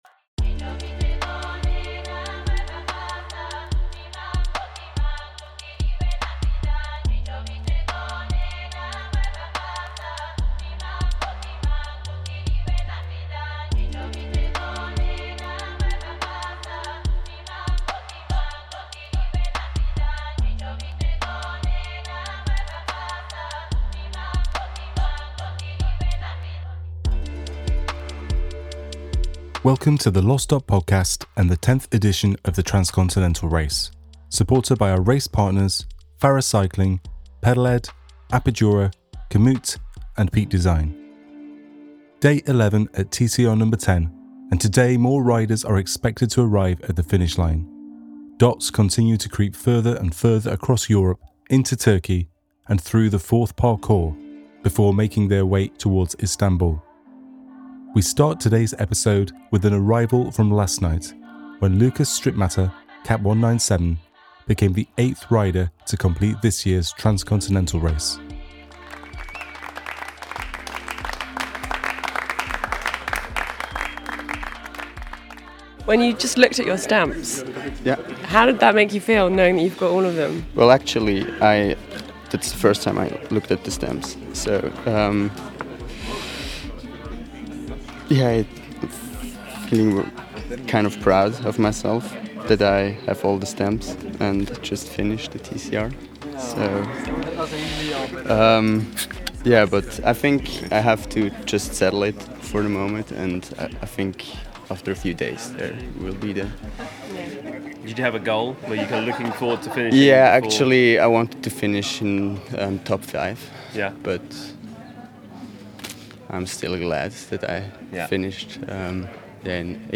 TCRNo10 | Day 11 Aug 02, 2024, 12:23 PM Headliner Embed Embed code See more options Share Facebook X Subscribe TCRNo10 // Day 11 Day 11 sees more celebrations in Istanbul, and we hear from the riders rounding out the top 10.
Further back, Control Car 2 hears from riders who are hoping to reach the end of their journeys soon. With a tough headwind and some last-minute mechanicals to contend with, there are plenty of reminders that the Race isn’t over until it’s over.